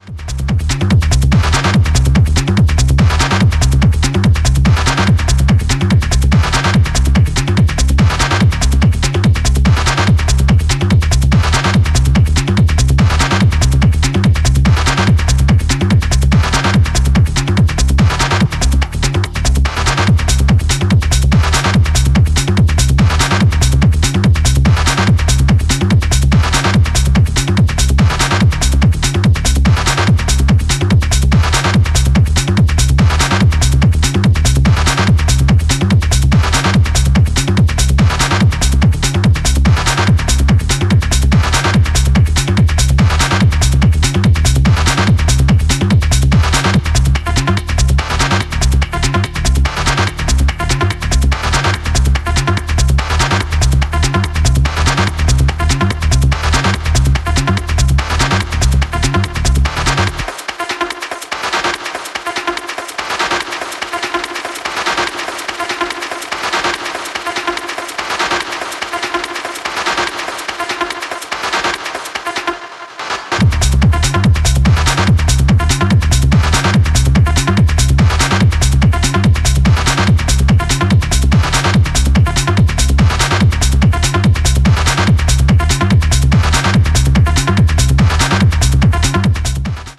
Highly effective, tribalish, grooving Techno force.